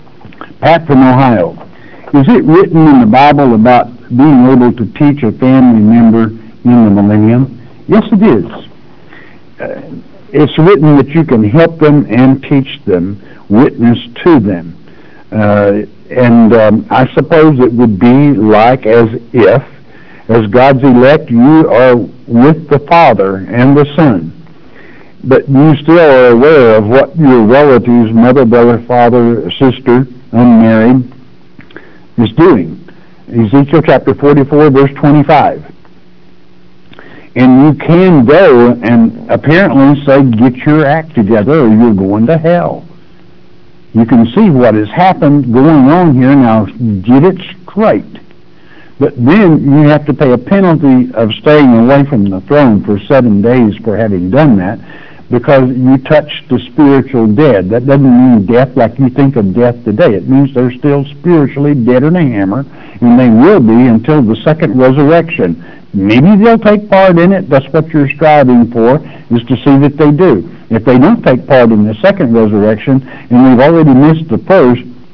Audio excerpt of a well-meaning pastor teaching
Though, to his credit, he seems uncertain (observe the use of the word "apparently" at 39 seconds into the audio-bite), and may be having second doubts himself.